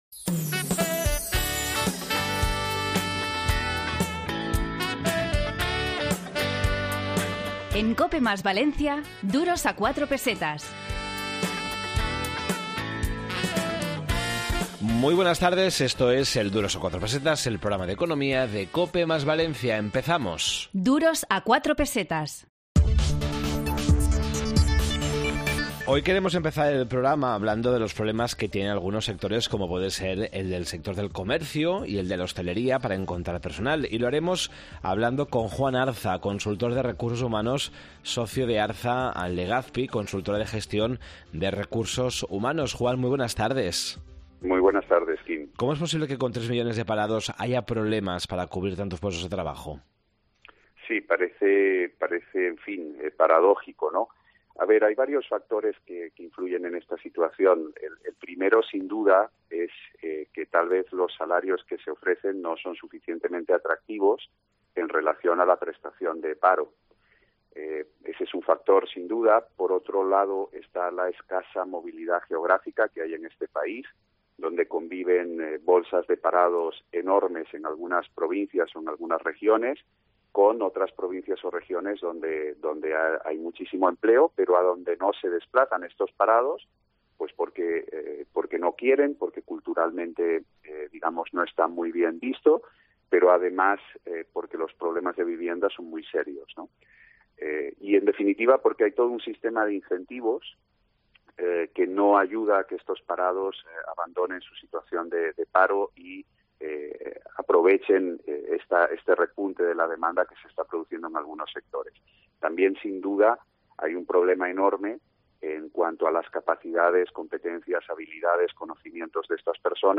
Esta semana, en Duros a 4 Pesetas de COPE + Valencia, en el 92.0 de la FM, hemos preparado un programa dedicado a las dificultades para cubrir vacantes laborales, la subida de los tipos de interés y los métodos de ahorro que permiten las viviendas.